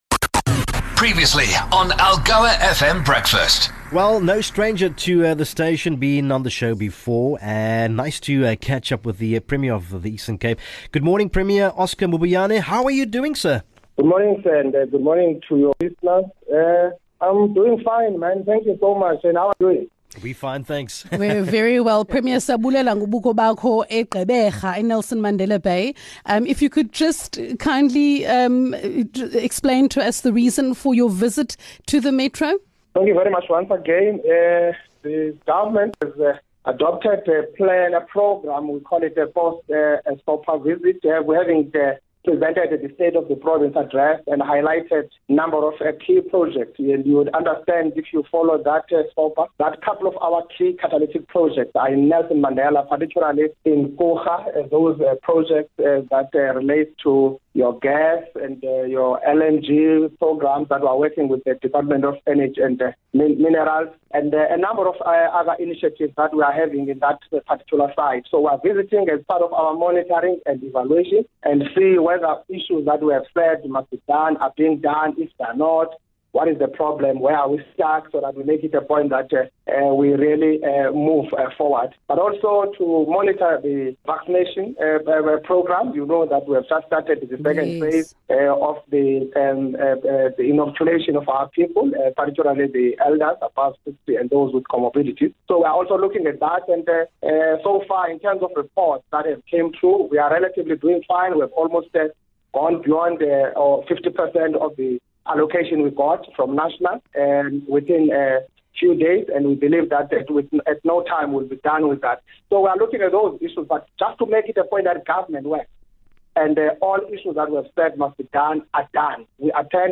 Premiere Mabuyane is on a visit to Nelson Mandela Bay post-SOPA - he joined the Breakfast Team to talk through various projects on the cards for the Bay.